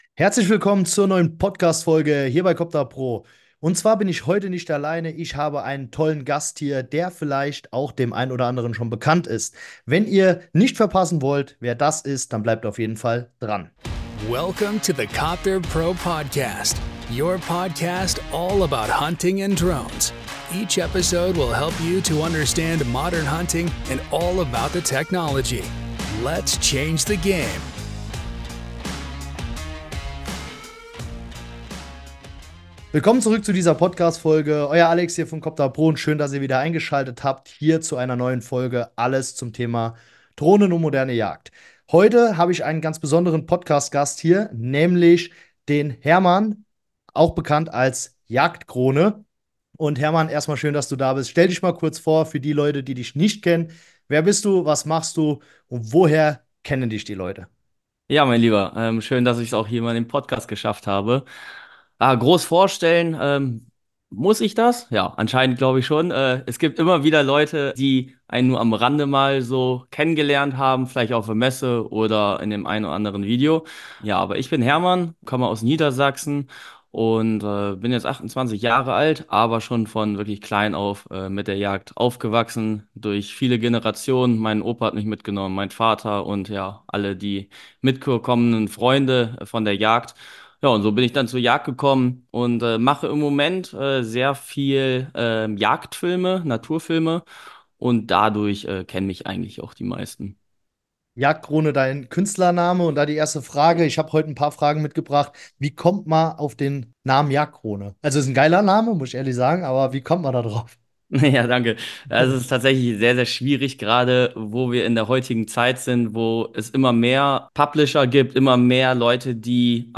Interview mit Jagdkrone - Der Drohneneinsatz bei der Jagd [1/2] ~ Copterpro Podcast: Alles zum Thema Drohnen und moderne Jagd Podcast